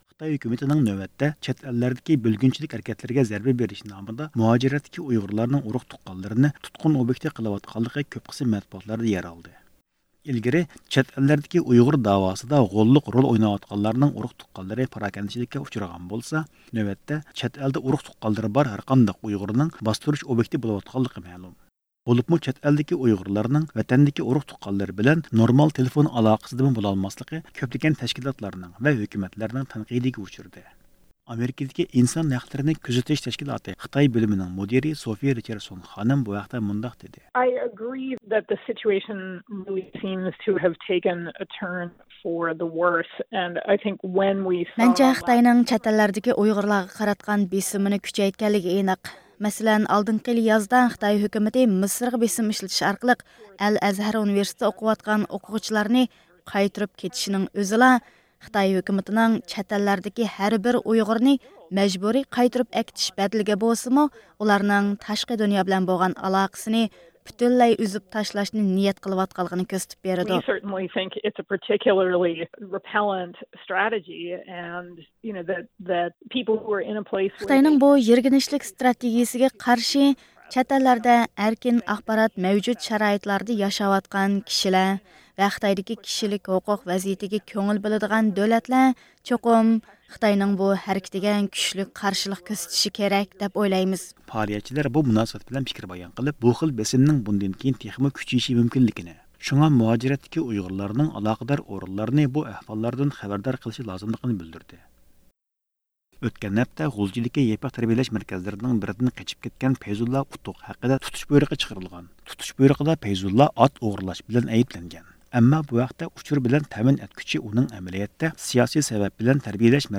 ھەپتىلىك خەۋەرلەر (31-مارتتىن 6-ئاپرېلغىچە) – ئۇيغۇر مىللى ھەركىتى